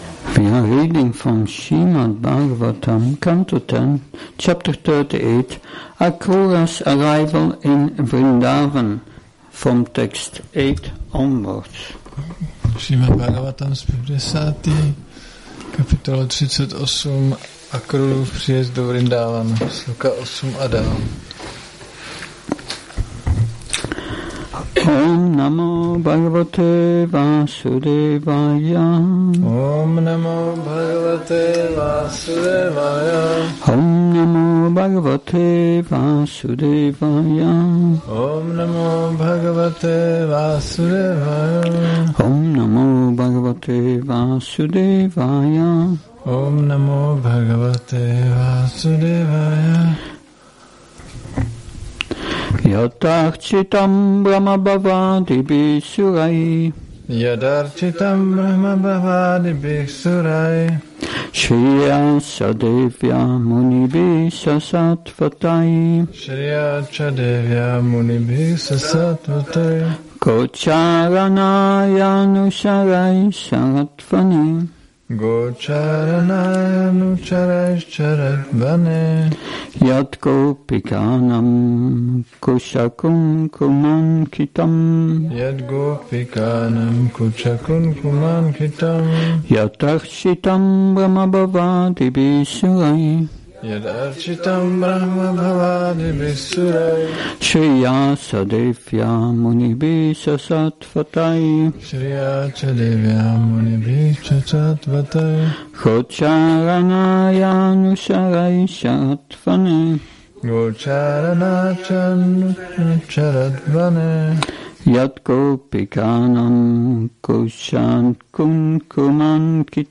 Šrí Šrí Nitái Navadvípačandra mandir
Přednáška SB-10.38.8